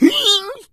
PigFall 07.ogg